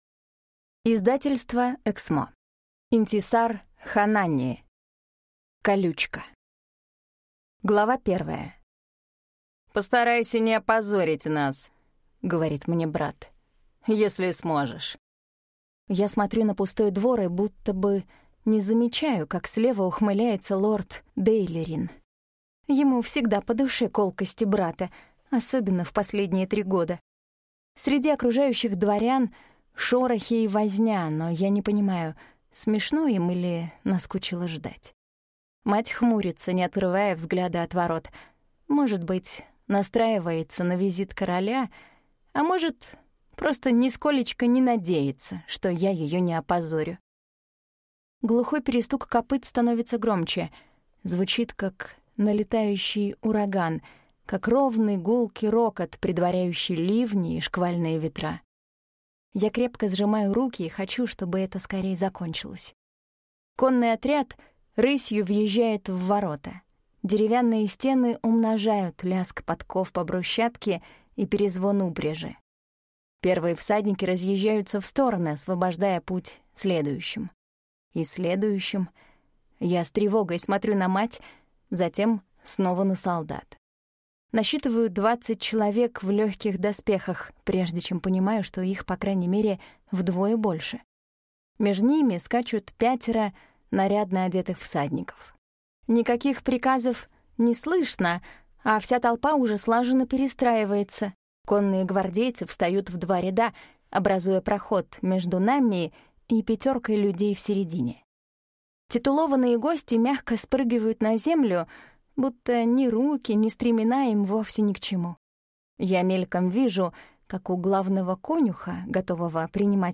Аудиокнига Колючка | Библиотека аудиокниг